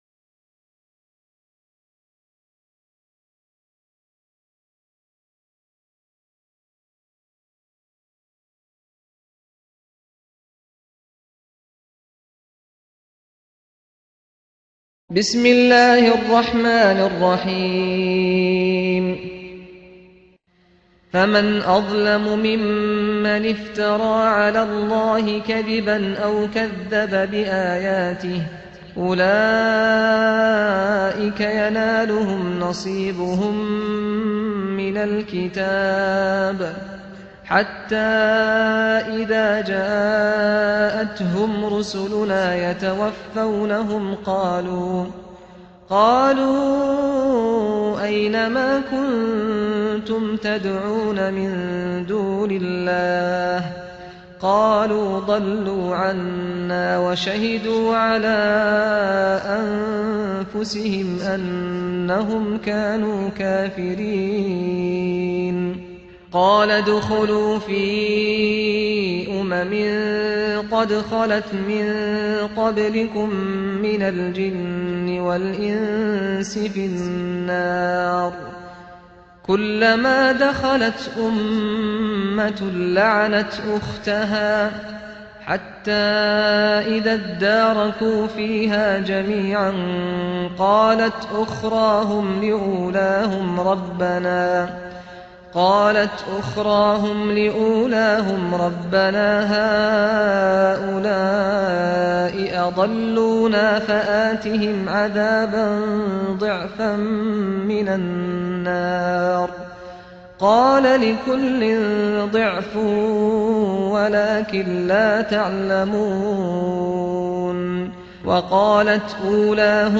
الدرس (18) تفسير سورة الأعراف - الشيخ محمد راتب النابلسي